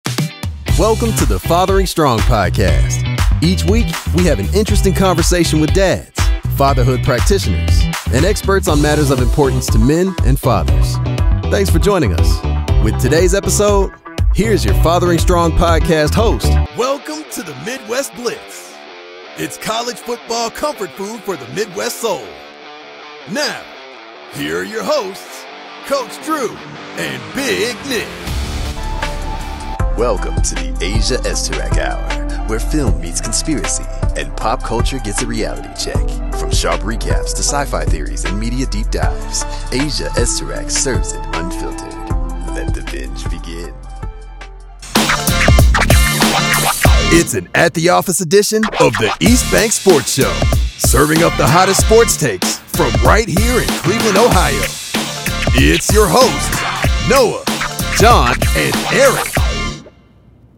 Podcast Intro Demo
Young Adult
Middle Aged
I record from a professional home studio and provide clean, broadcast-ready audio with fast turnaround, clear communication, and attention to detail from start to finish.